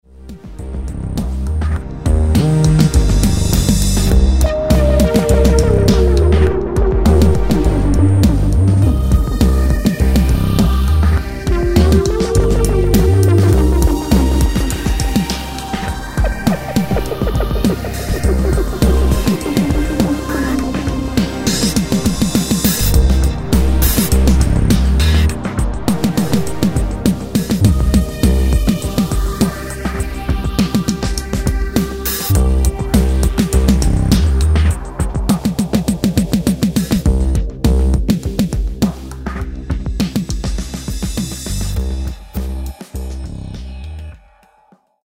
royalty free music